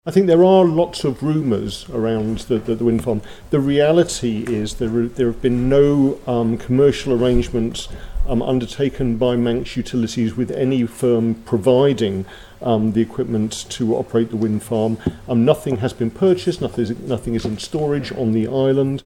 Alex Allinson told the House of Keys no commercial arrangements have been put in place in relation to Cair Vie.
With a planning application yet to be submitted, Dr Allinson says the project is still in its development phase: